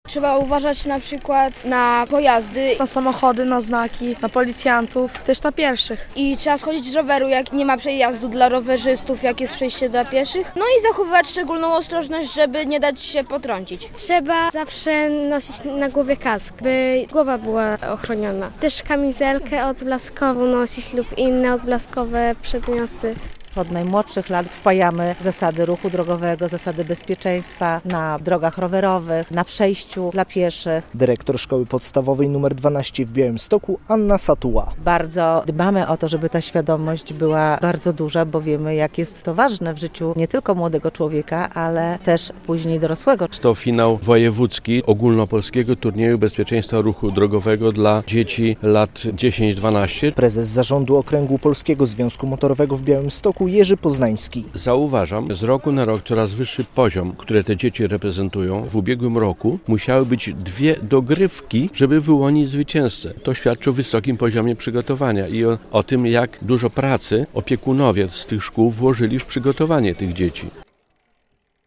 Turniej Bezpieczeństwa w Ruchu Drogowym dla uczniów szkół podstawowych - relacja